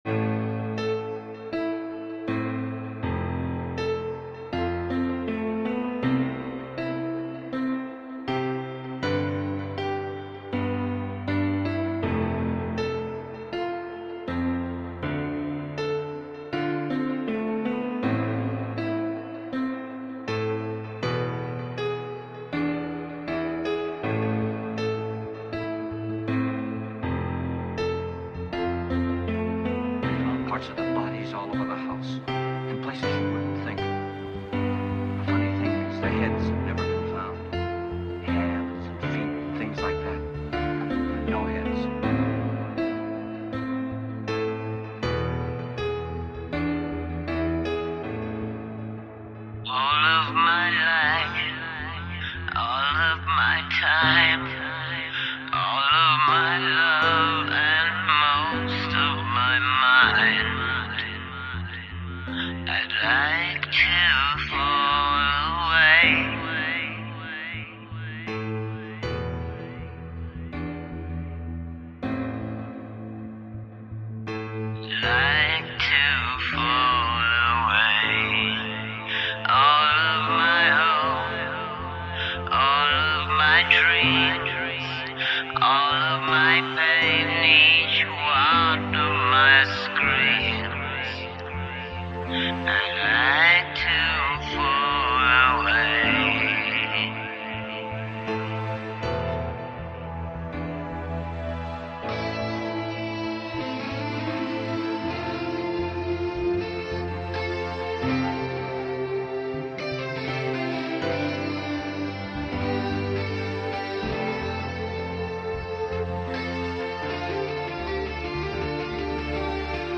The melancholy mood and space.